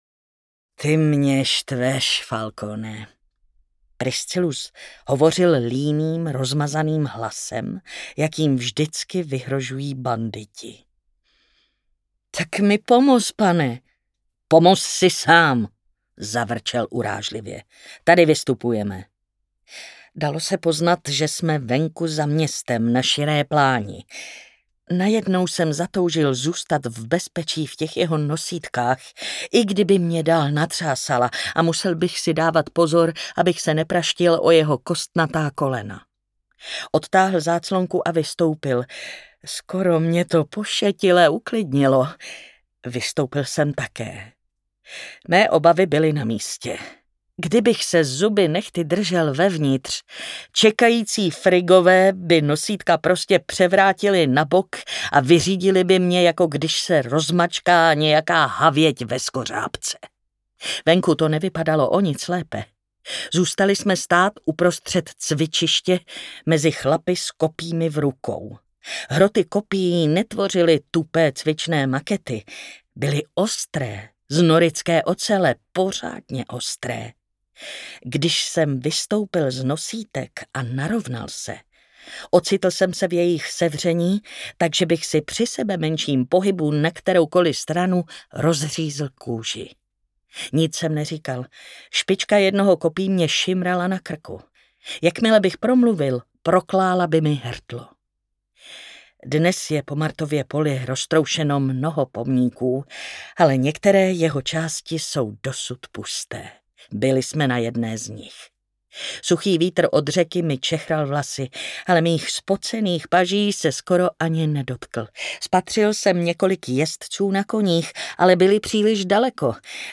Audiobook
Read: Martina Hudečková